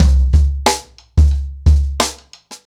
Expositioning-90BPM.11.wav